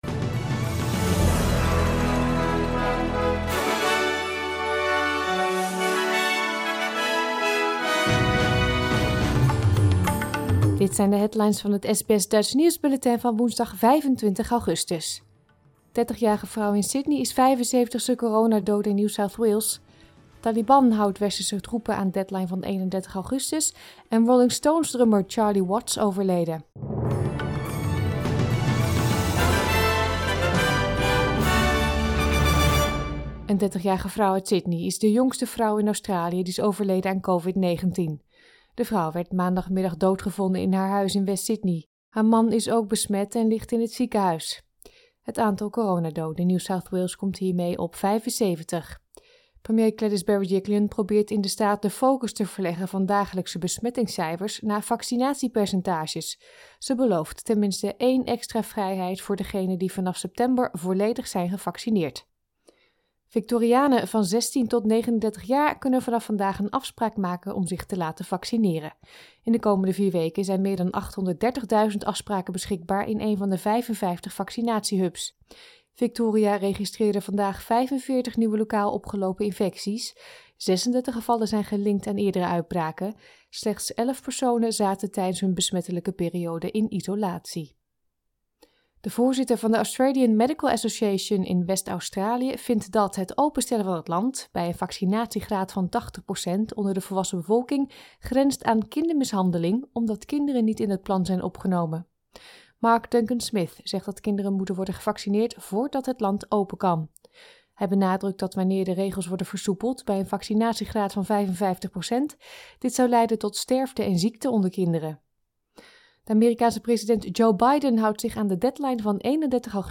Nederlands/Australisch SBS Dutch nieuwsbulletin van woensdag 25 augustus 2021